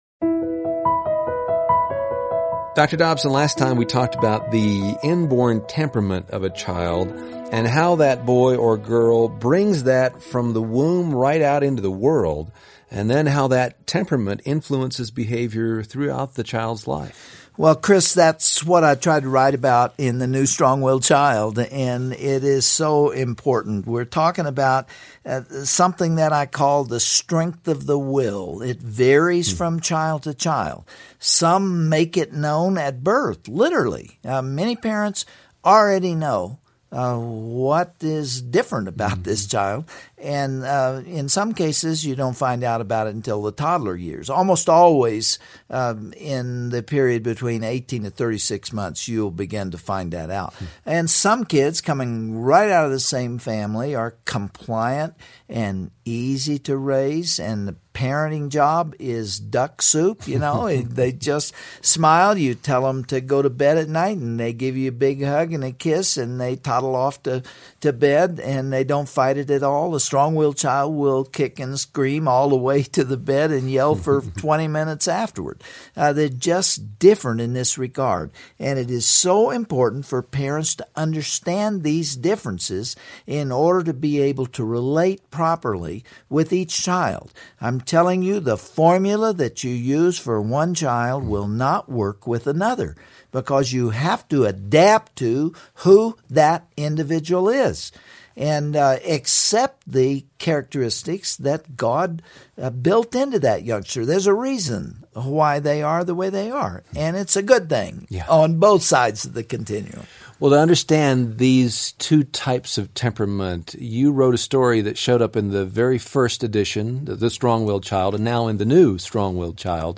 Bonus Content: Listen to Dr. James Dobson's commentary, "Temperaments Influence the Life of a Child."